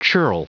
Prononciation du mot churl en anglais (fichier audio)
Prononciation du mot : churl